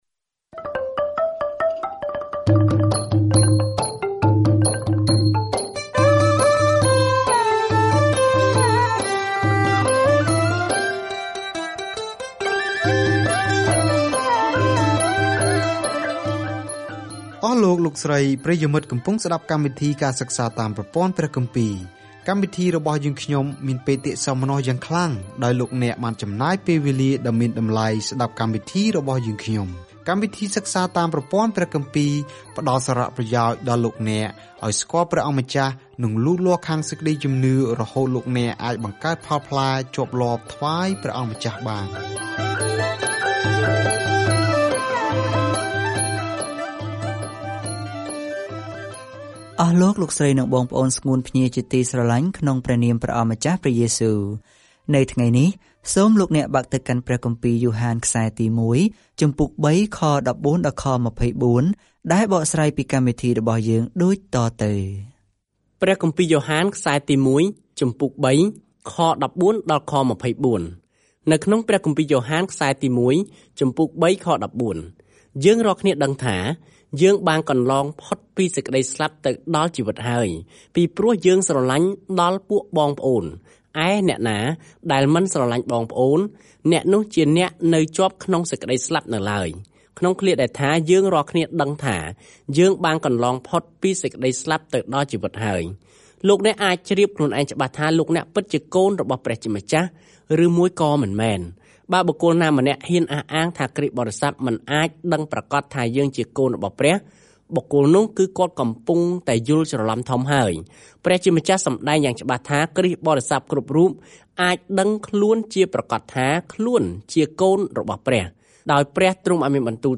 ការធ្វើដំណើរប្រចាំថ្ងៃតាមរយៈ យ៉ូហានទី 1 នៅពេលអ្នកស្តាប់ការសិក្សាជាសំឡេង ហើយអានខគម្ពីរដែលជ្រើសរើសចេញពីព្រះបន្ទូលរបស់ព្រះ។